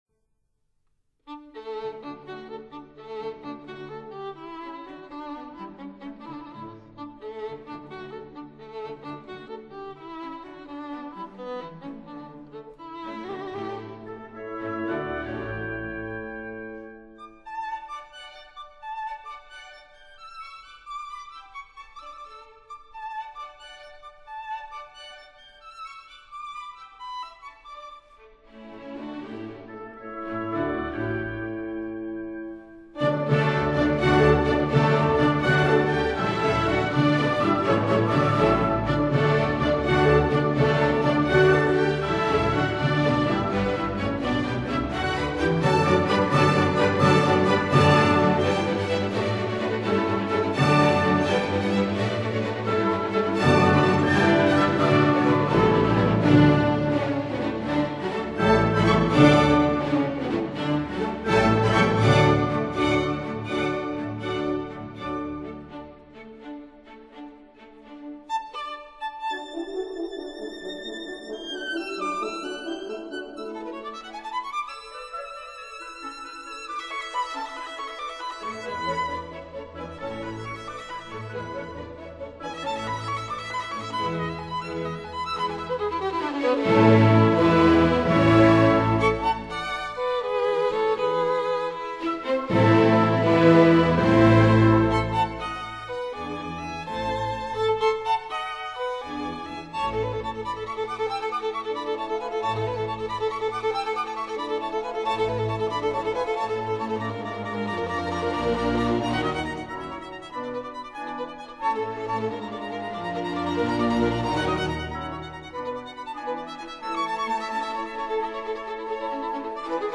D major